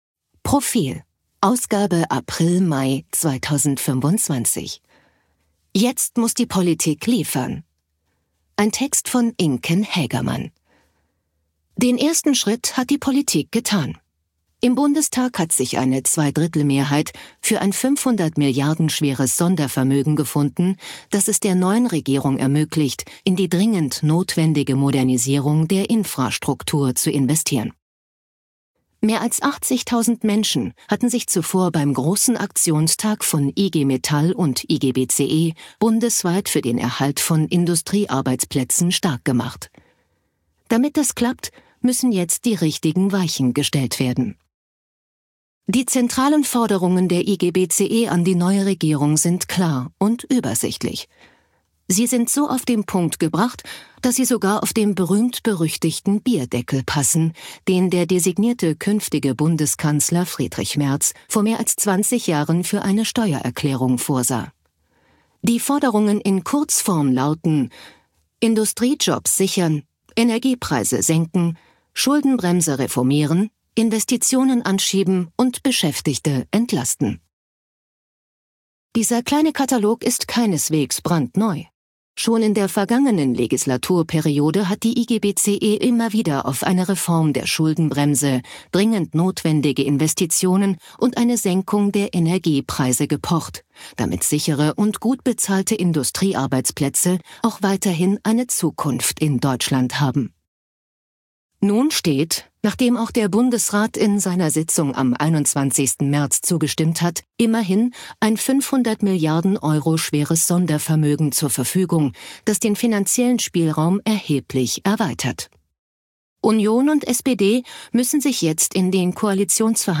Artikel vorlesen lassen ▶ Audio abspielen